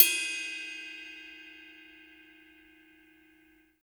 D2 RIDE-12-L.wav